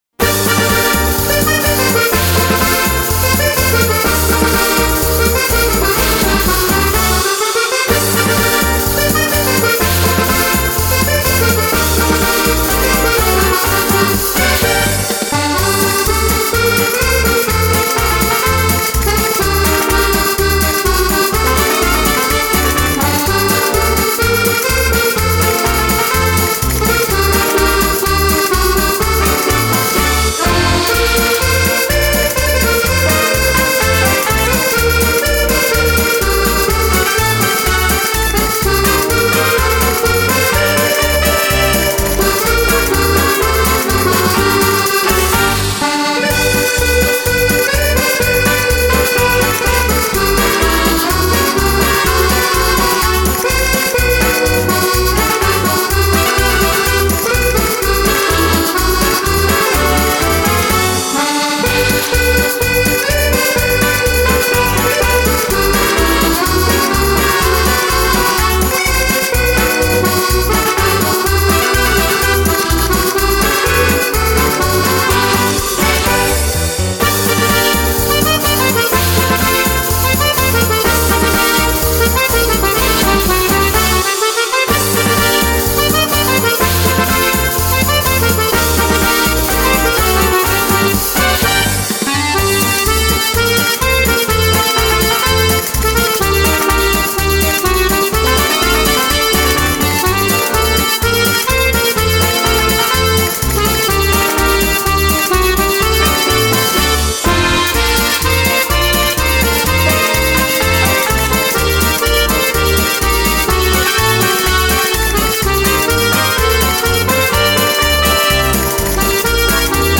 version accordéon